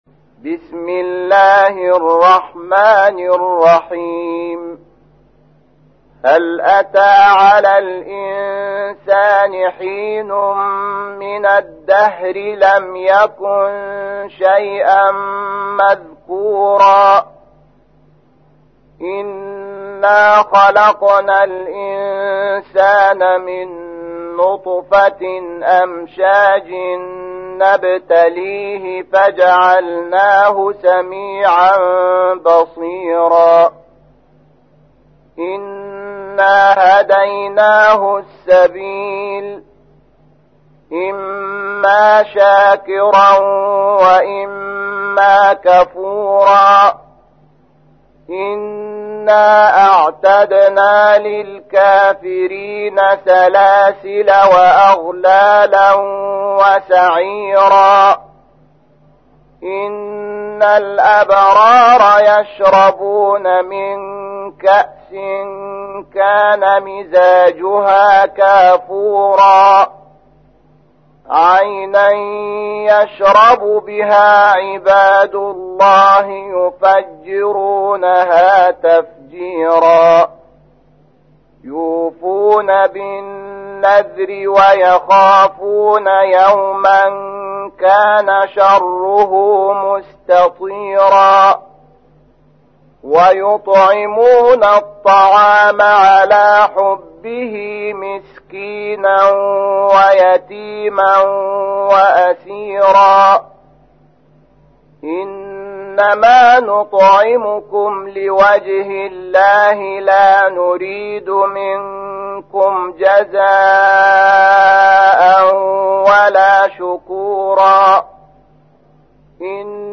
تحميل : 76. سورة الإنسان / القارئ شحات محمد انور / القرآن الكريم / موقع يا حسين